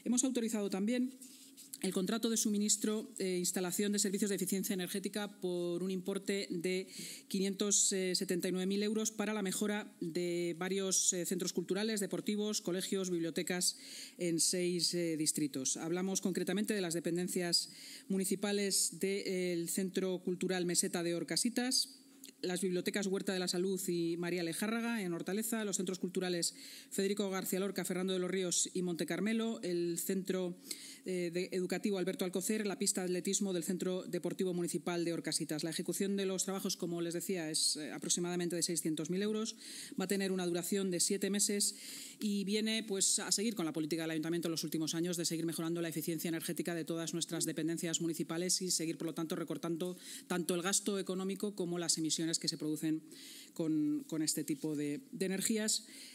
Nueva ventana:La vicealcaldesa y portavoz municipal, Inma Sanz: